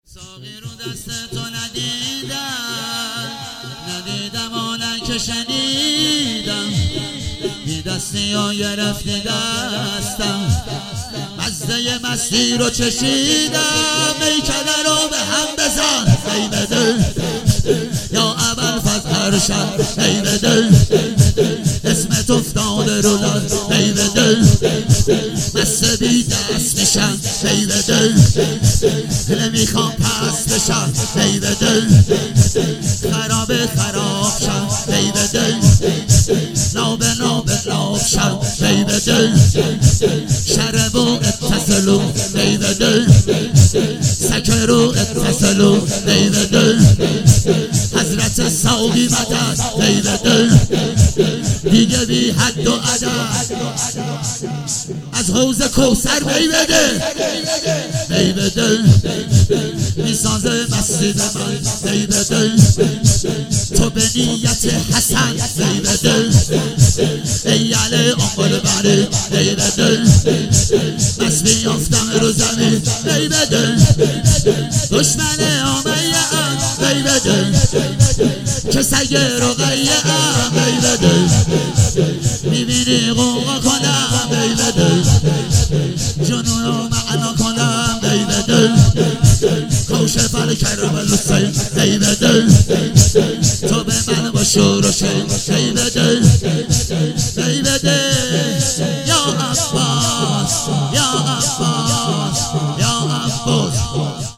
شب آخر صفر 97 - شور - ساقی رو دست تو ندیدم